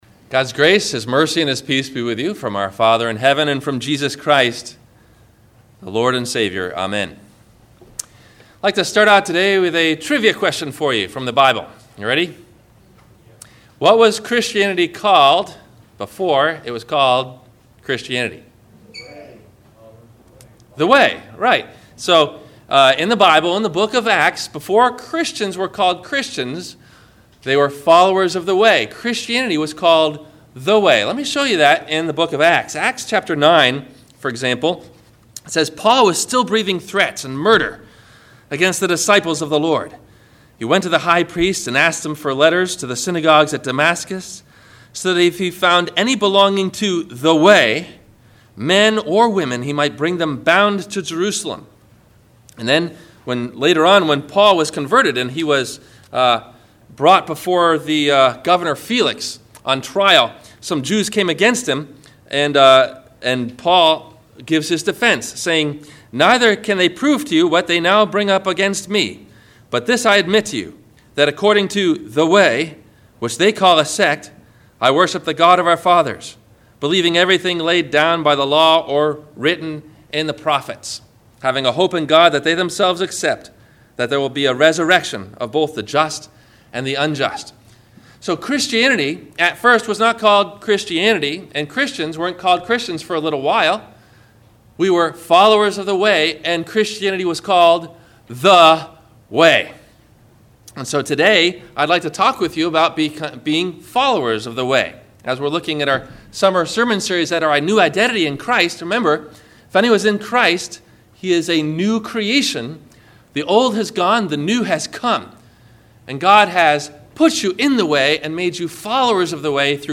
Followers of The Way - Sermon - August 05 2012 - Christ Lutheran Cape Canaveral